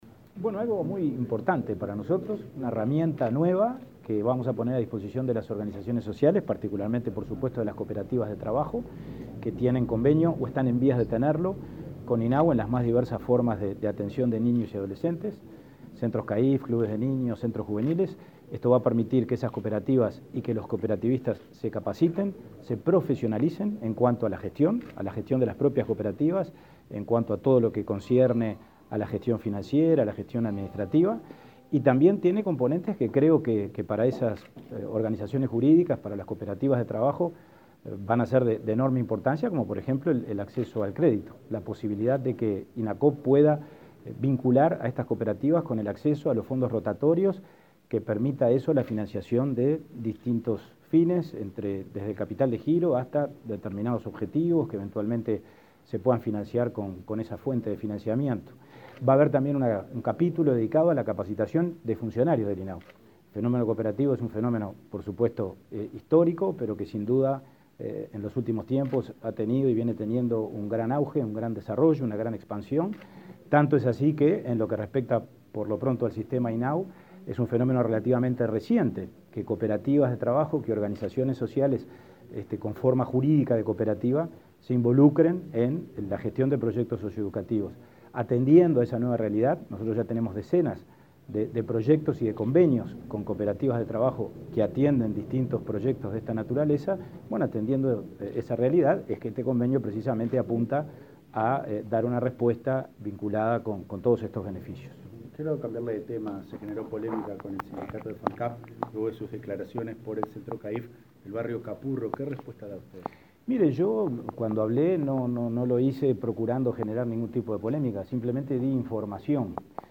Declaraciones a la prensa del presidente del INAU, Pablo Abdala
Este martes 14, los presidentes del Instituto del Niño y Adolescente del Uruguay (INAU), Pablo Abdala, y el Instituto Nacional del Cooperativismo (Inacoop), Martín Fernández, firmaron un convenio, cuya finalidad es capacitar a nuevas organizaciones para facilitar la gestión de los centros de infancia y adolescencia. Luego, Abdala dialogó con la prensa.